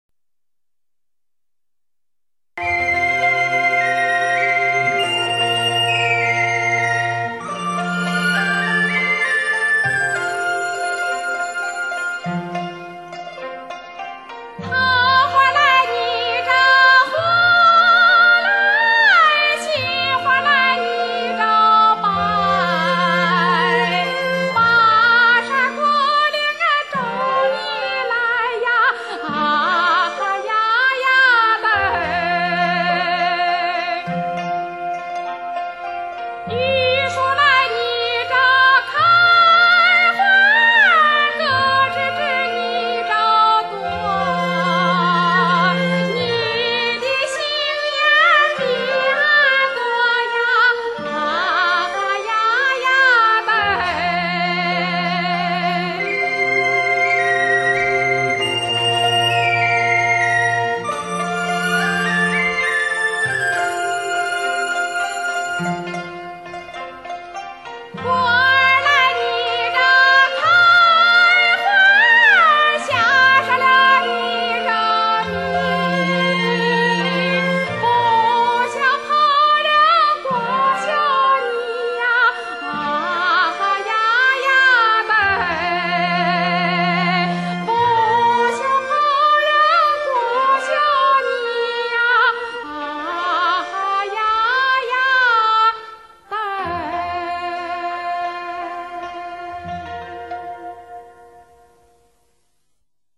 [2006-9-2]原汁原味的纯美山西左权民歌--桃花红 杏花白
山西左权民歌